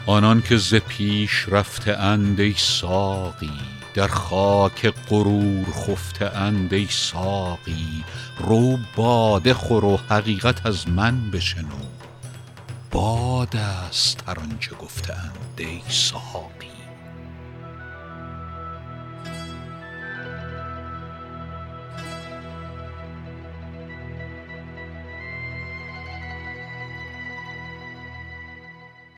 رباعی ۱۳ به خوانش فریدون فرح‌اندوز